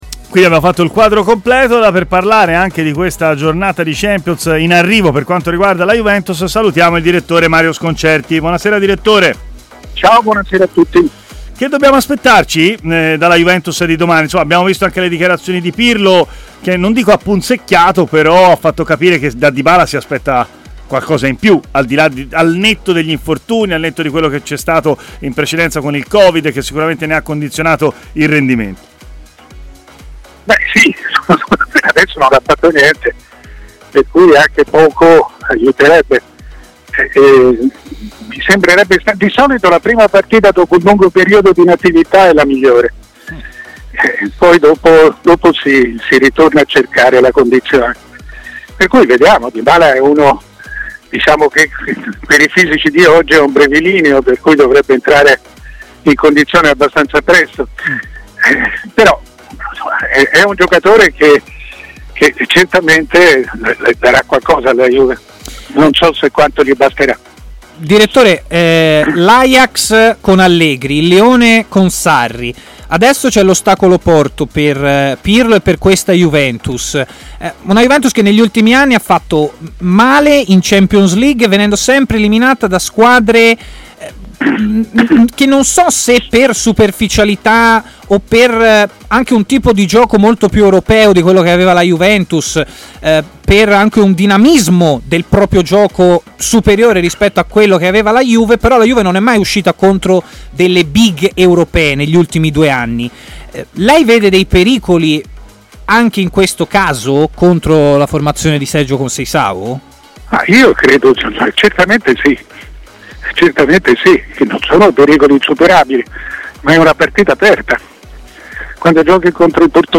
Il direttore Mario Sconcerti è intervenuto in diretta a TMW Radio, nel corso della trasmissione Stadio Aperto